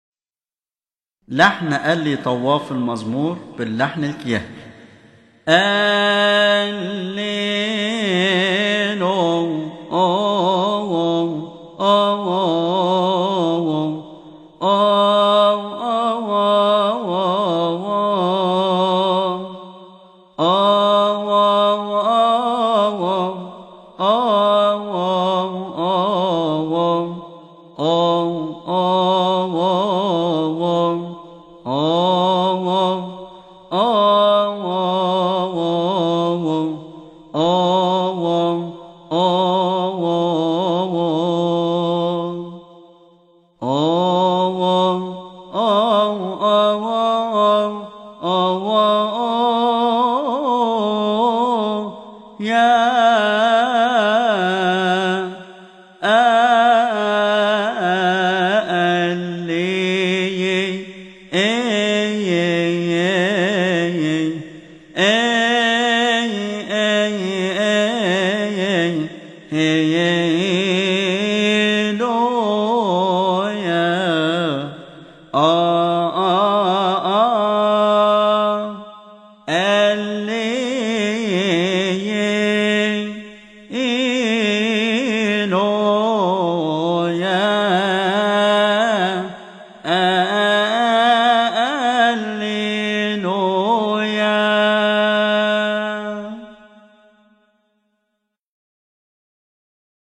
استماع وتحميل لحن التوزيع الكيهكى من مناسبة keahk